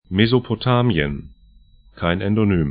Mesopotamien mezopo-
'ta:mĭən   ‘Ard al Jazīrah